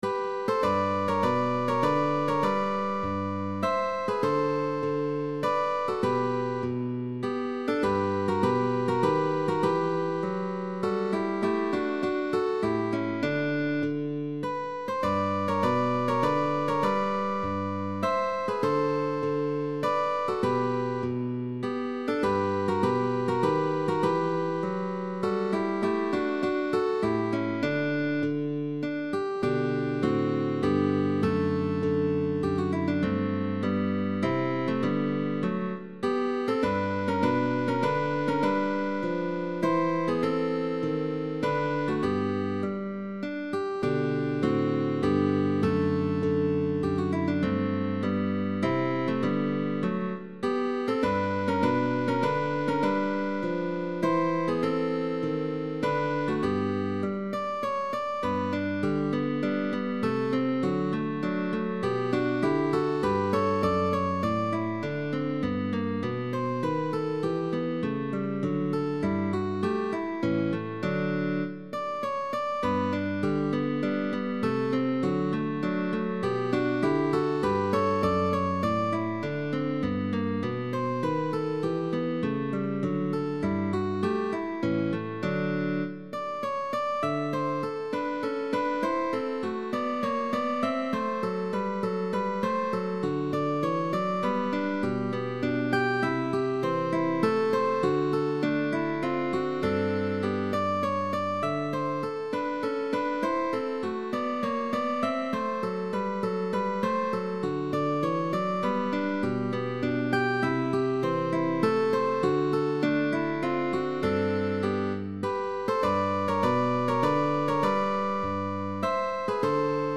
GUITAR TRIO
XIX Century